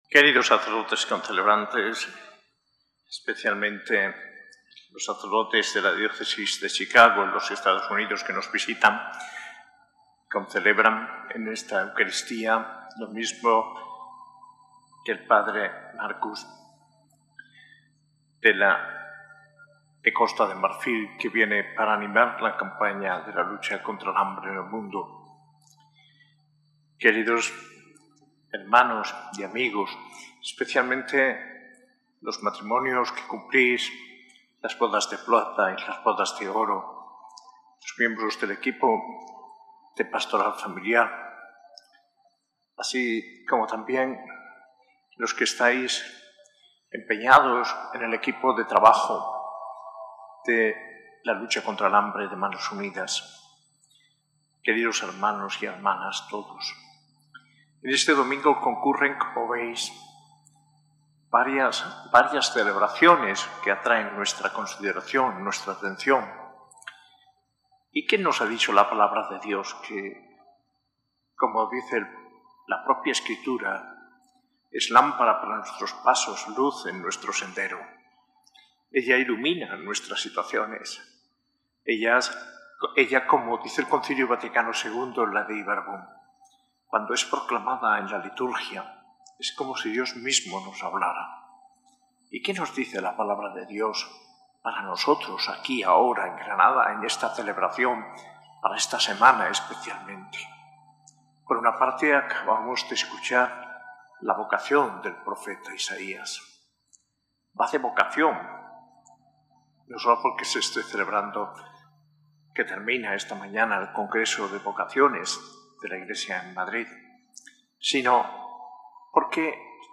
Homilía del arzobispo de Granada, Mons. José María Gil Tamayo, en la Eucaristía celebrada el 9 de febrero de 20205, V Domingo del Tiempo Ordinario. En este día también se celebró Jornada Nacional de Manos Unidas y con la Pastoral Familiar, los matrimonios que cumplían 25 y 50 años de casados renovaron sus promesas matrimoniales.